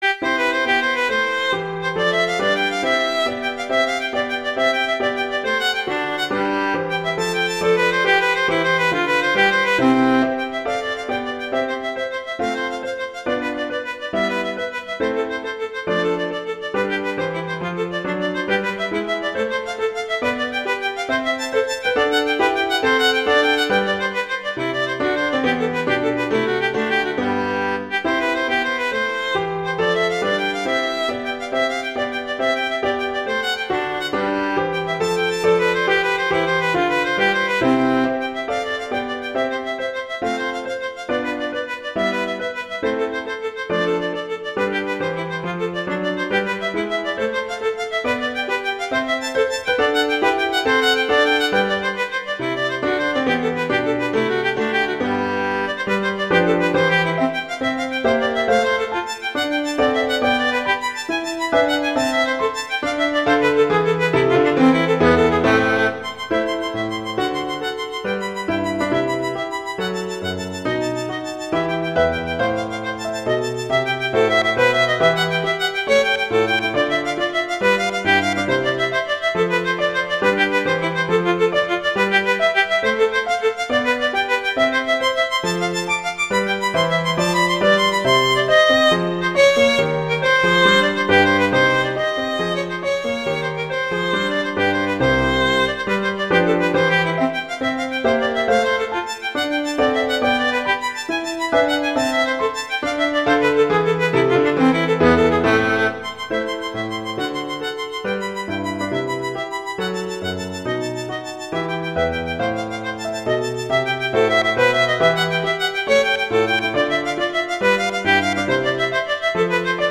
violin and piano
classical
GIGA Allegro